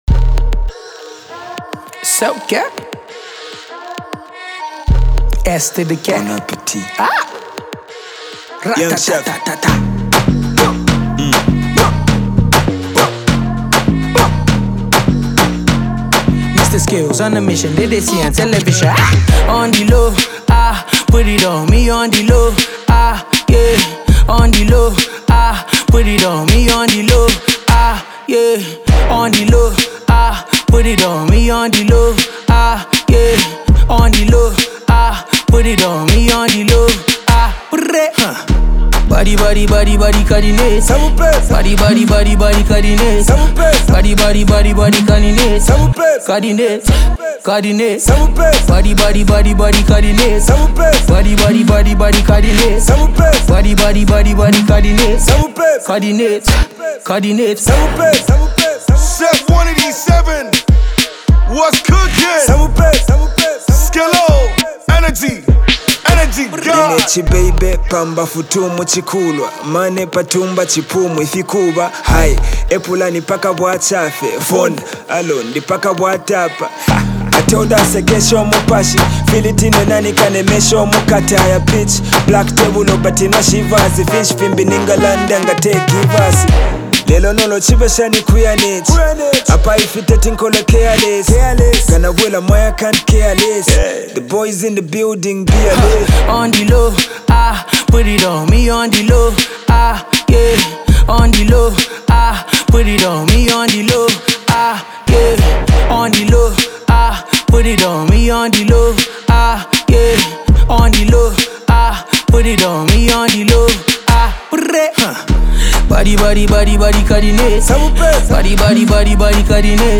hip-hop
melodious